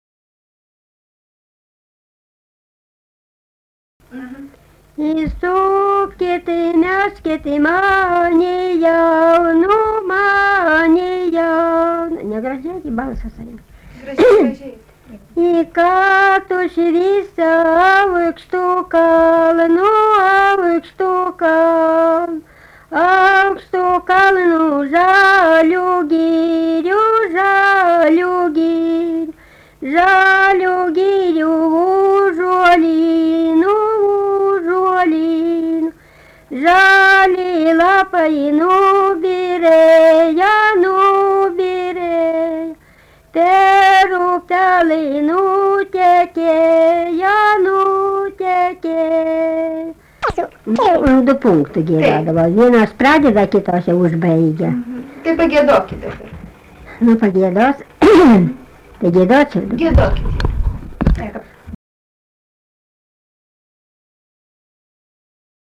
daina kalendorinių apeigų ir darbo Erdvinė aprėptis Jakeliai
Atlikimo pubūdis vokalinis